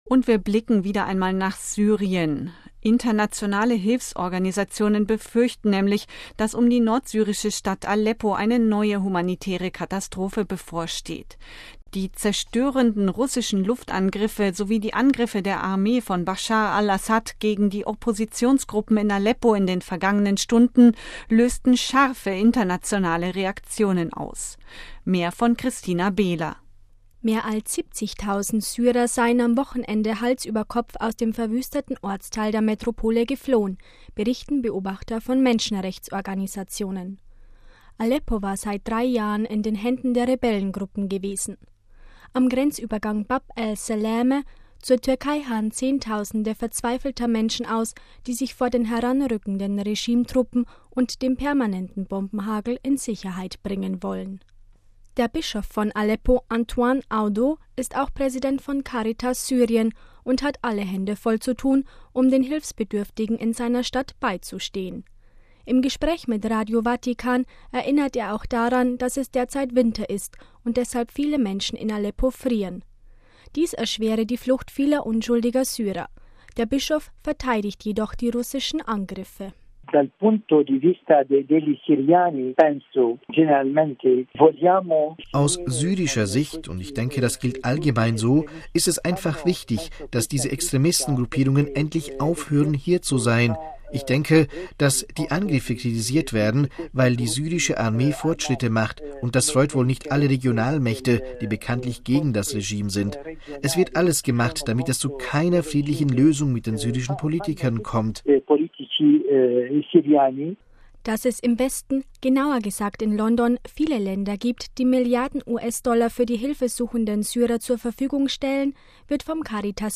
Im Gespräch mit Radio Vatikan erinnert er auch daran, dass es derzeit Winter ist und deshalb viele Menschen in Aleppo frieren.